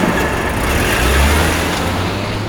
50 Cent Car FX.wav